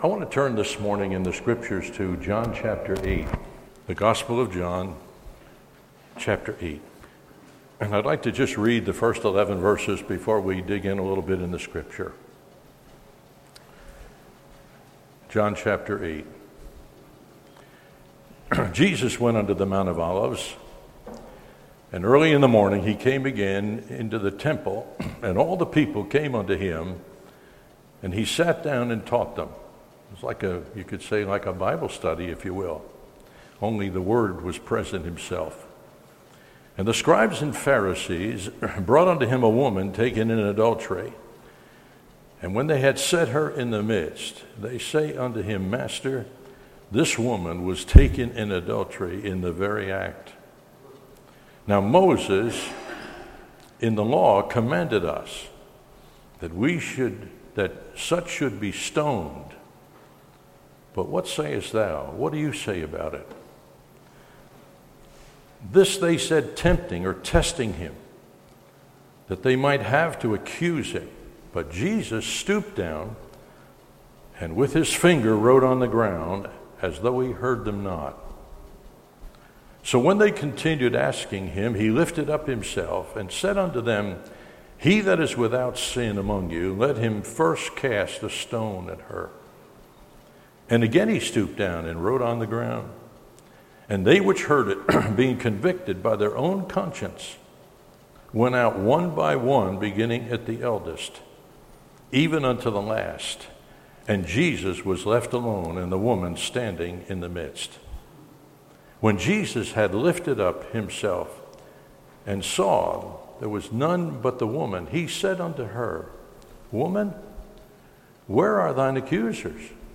Passage: John 8:1-11 Session: Morning Devotion